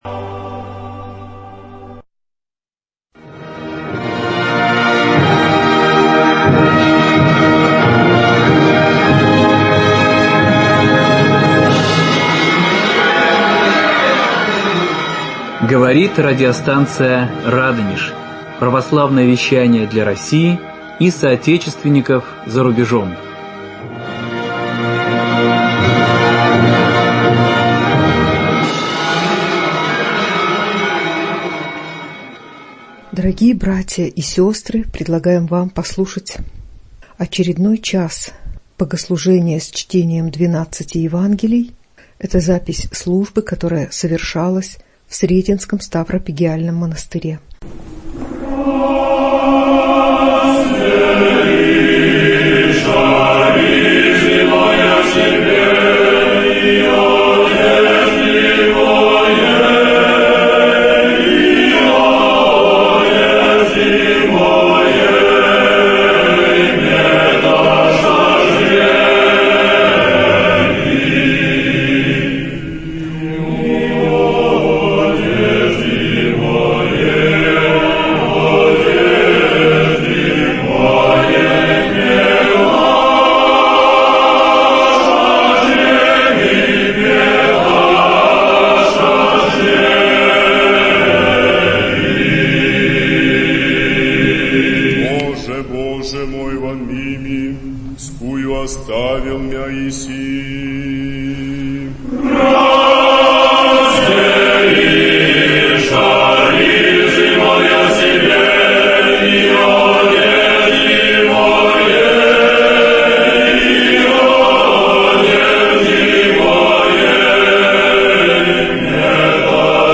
Фрагменты утрени Великой пятницы с чтением 12 Евангелий в Сретенском монастыре ч.3